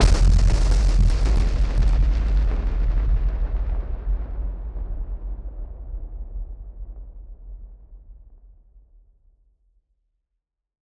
BF_SynthBomb_A-05.wav